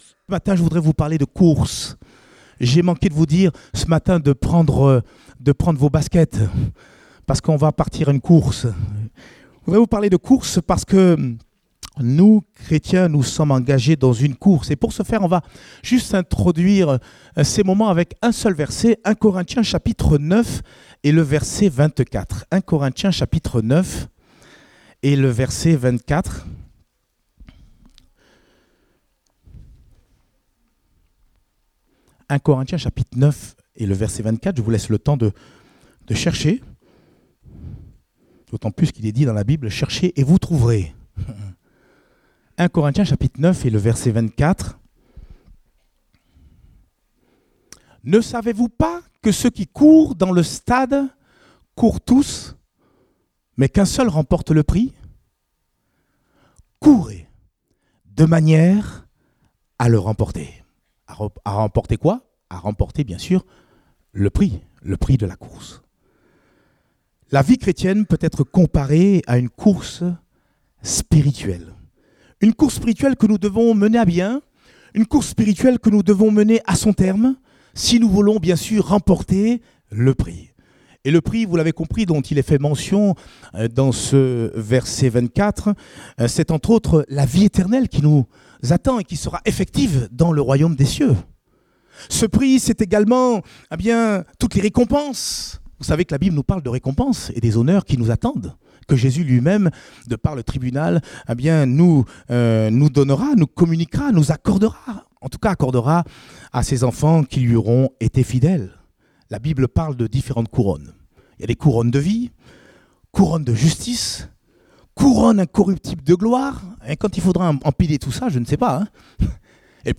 Date : 11 novembre 2018 (Culte Dominical)